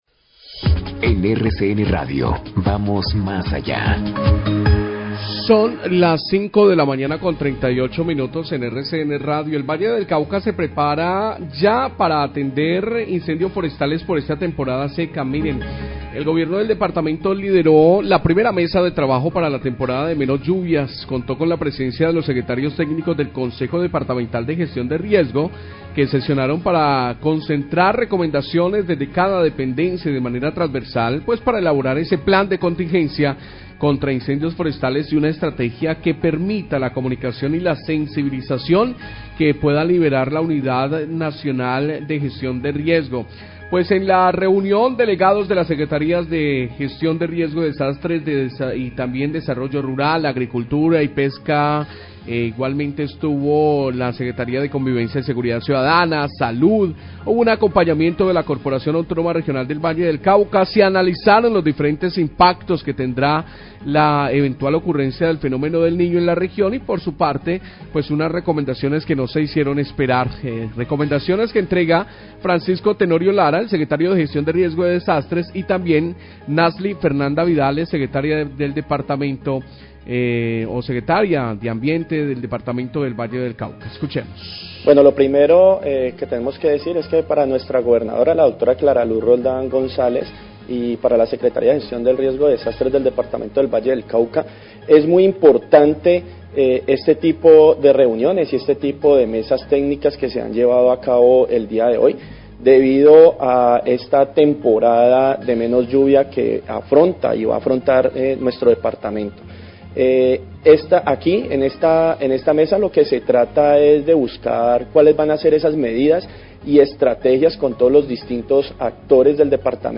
Radio
Francisco Tenorio, director Oficina de Gestión del Riesgo Valle, y Nasly Vidales, Secretaria de Ambiente Valle, hablan de la mesa técnica para concertar recomendaciones y el plan de contingencia frente a la temporada seca y la ocurrencia dl Fenómeno del Niño.